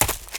STEPS Leaves, Run 20.wav